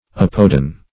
Meaning of apodan. apodan synonyms, pronunciation, spelling and more from Free Dictionary.
apodan - definition of apodan - synonyms, pronunciation, spelling from Free Dictionary Search Result for " apodan" : The Collaborative International Dictionary of English v.0.48: Apodan \Ap"o*dan\, a. (Zool.)